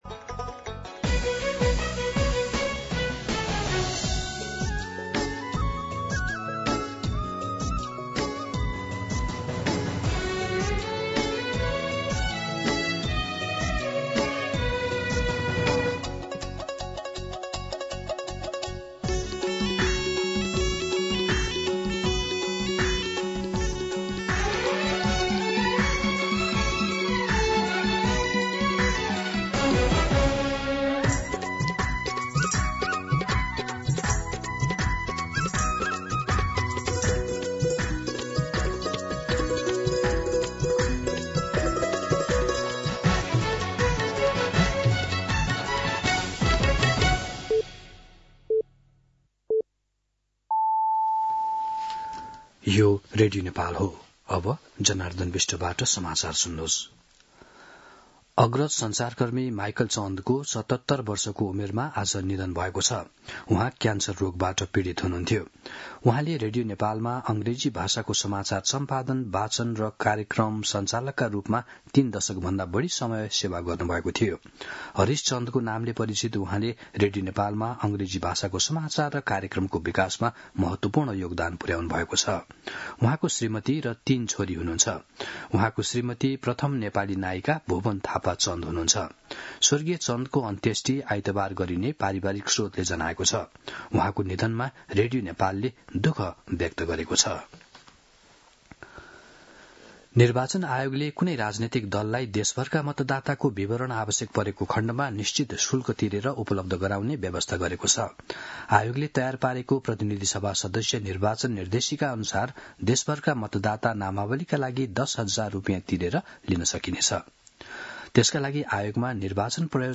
मध्यान्ह १२ बजेको नेपाली समाचार : २५ पुष , २०८२